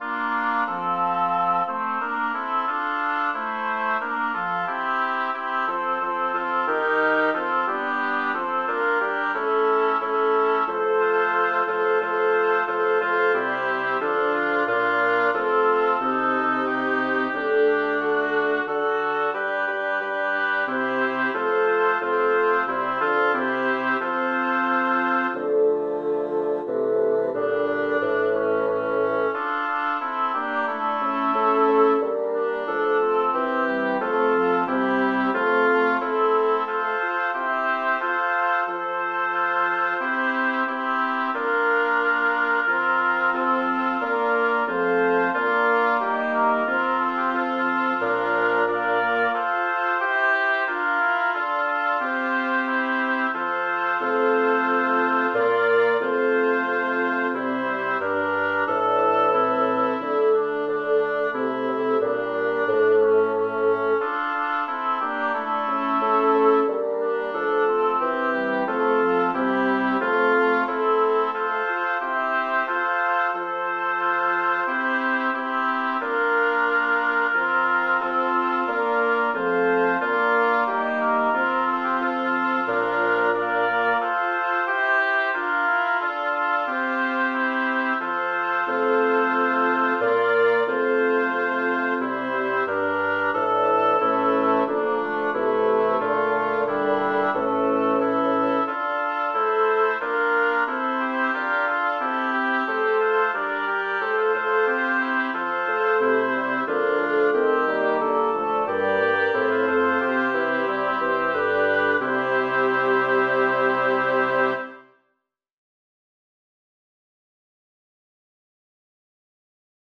Title: Se'l dolce sguardo di costei Composer: Philippe de Monte Lyricist: Francesco Petrarca Number of voices: 6vv Voicing: SAATTB Genre: Secular, Madrigal
Language: Italian Instruments: A cappella